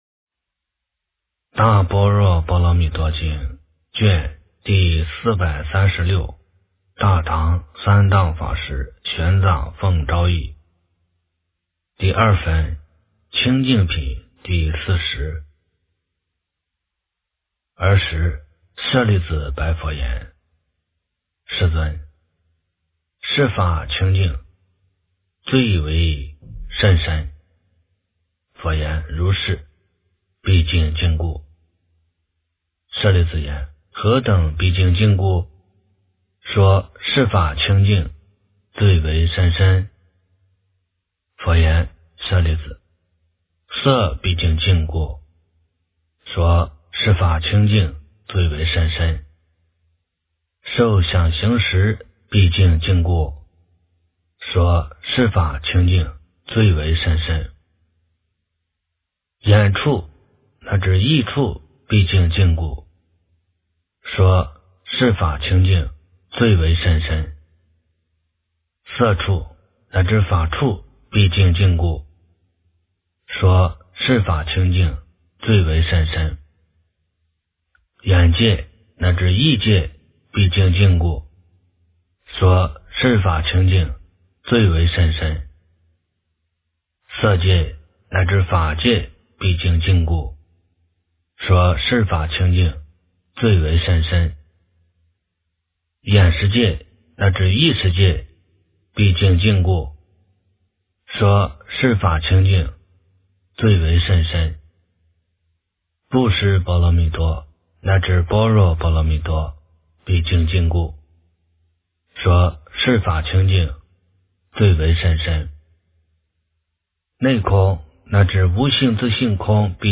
大般若波罗蜜多经第436卷 - 诵经 - 云佛论坛